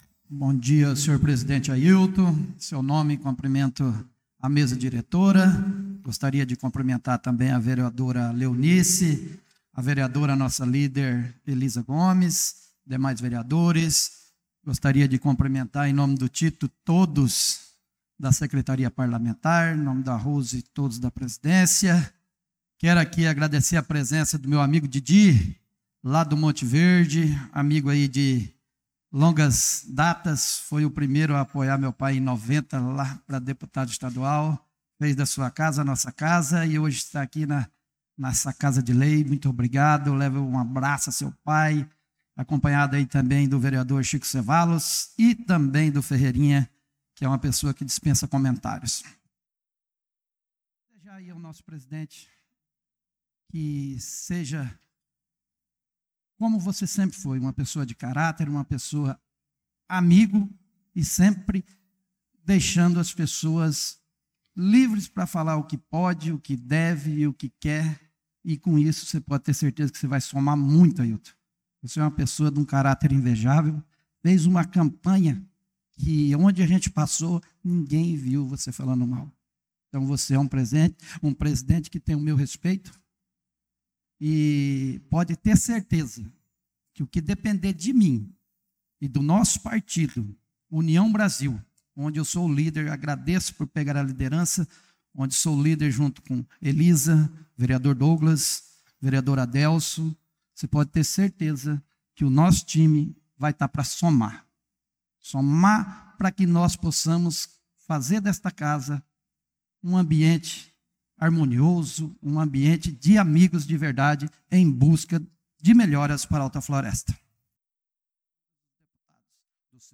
Pronunciamento do vereador Marcos Menim na Sessão Ordinária do dia 04/02/2025
Ver. Menin - completo com parte sem som.wav